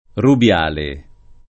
rubiale [ rub L# le ]